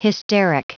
Prononciation du mot hysteric en anglais (fichier audio)
Prononciation du mot : hysteric